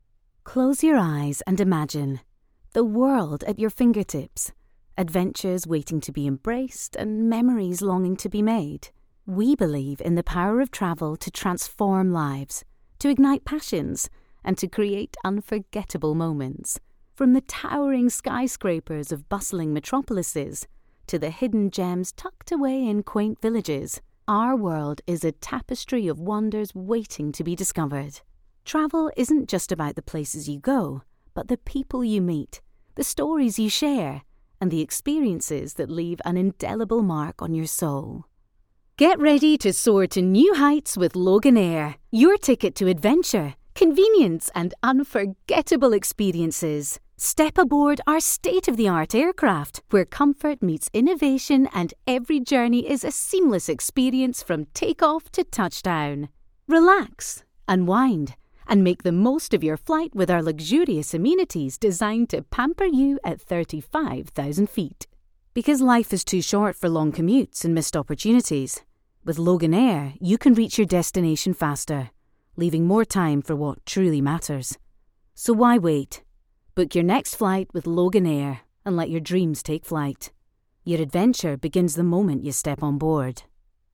English (Scottish)
Warm
Fresh
Professional